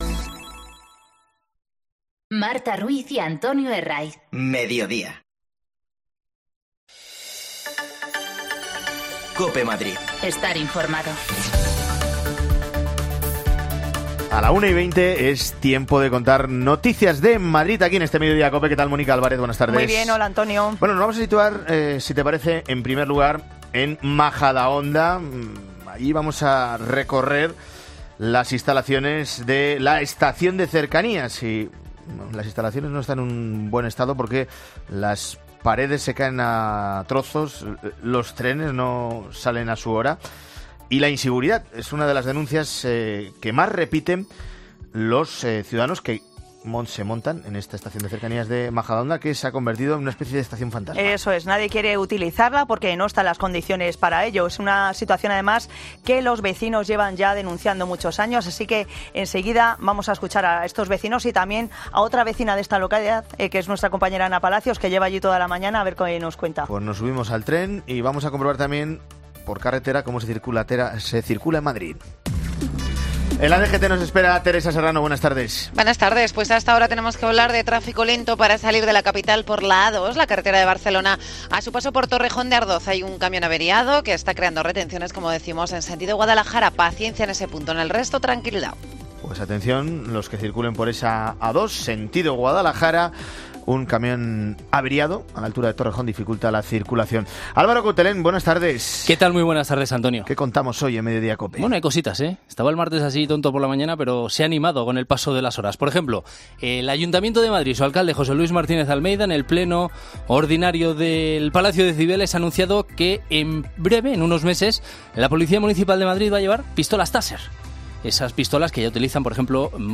AUDIO: Noas hemos acercado a la estación de cercanías de Majadahonda.. Los vecinos denuncian su abandono desde hace años.